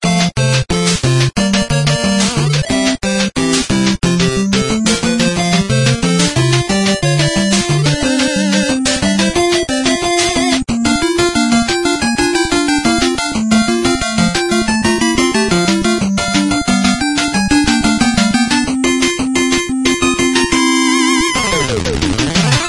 game_happy_end.mp3